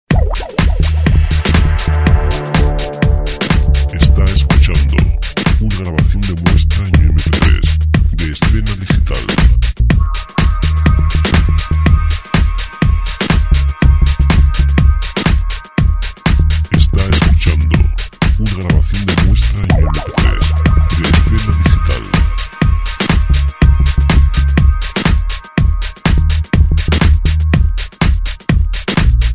Royalty free dance music